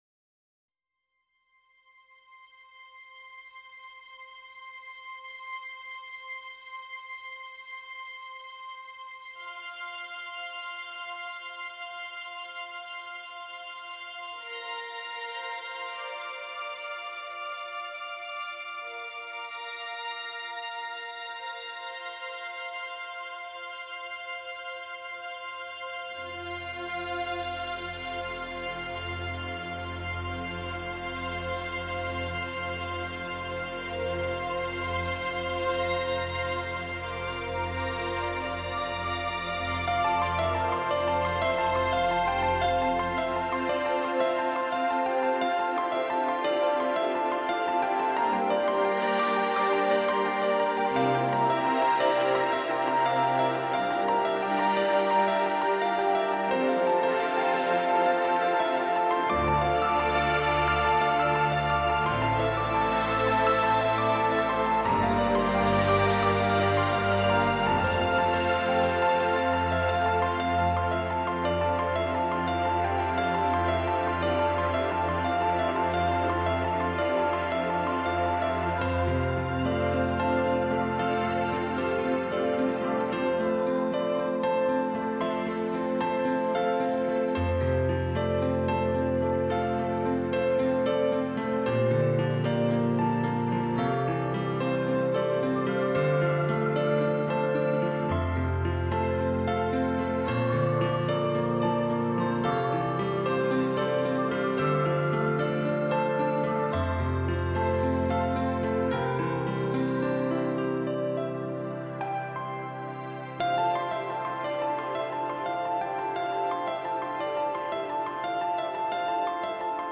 其中电子合成乐与真实钢琴交杂出的空间感